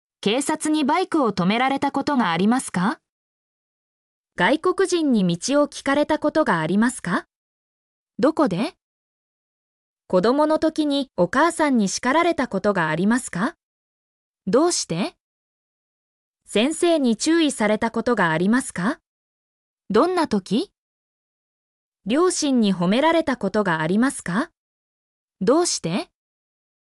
mp3-output-ttsfreedotcom-6_HM1S61Em.mp3